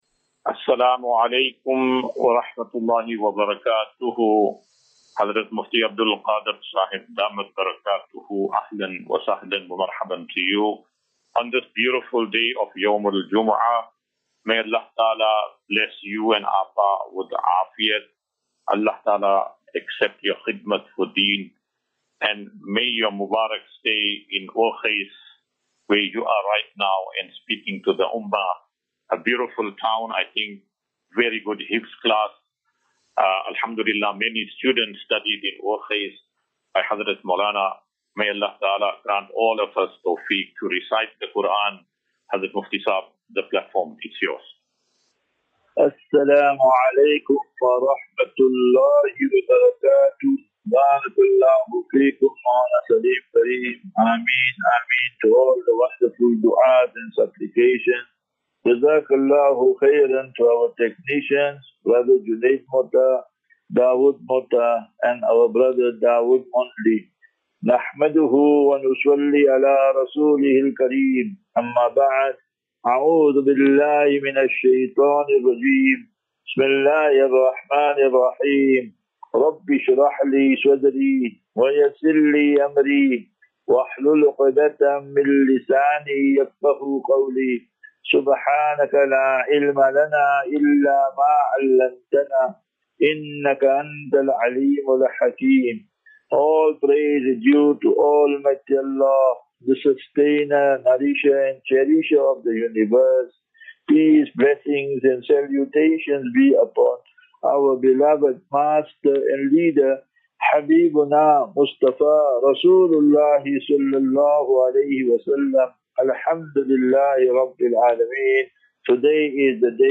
21 Feb 21 February 2025. Assafinatu - Illal - Jannah. QnA